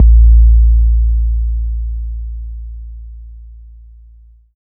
808 DRONE K.wav